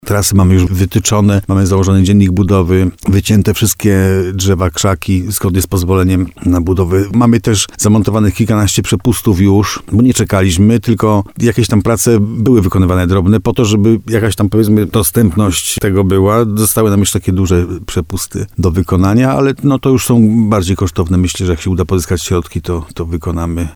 Wójt gminy Dobra Benedykt Węgrzyn przekonuje, że wszystko jest gotowe, aby projekt został ostatecznie zakończony.